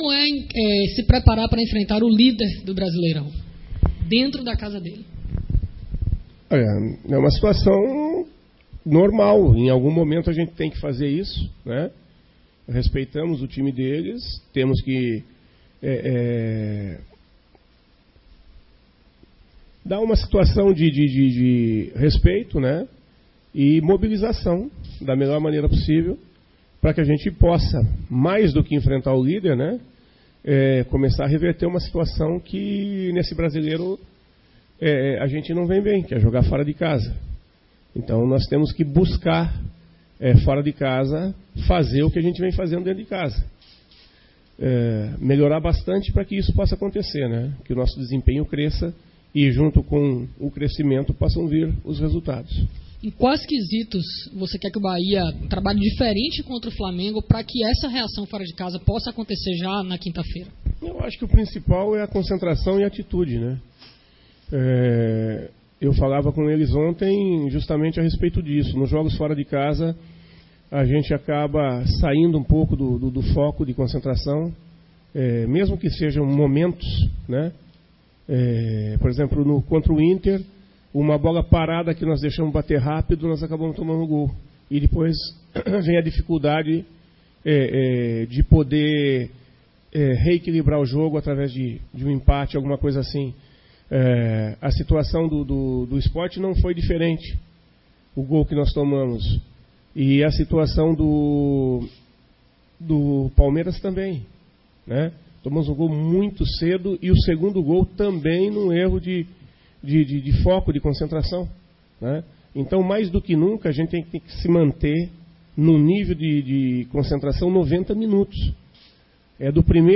O técnico Guto Ferreira foi alvo de entrevista hoje pela manha no Fazendão. Basicamente o treinador tricolor abordou as dificuldades de o Bahia vencer fora de casa. Segundo o treinador falta concentração, além disso, garante que não existe uma orientação diferente, a forma de propor o jogo é a mesma.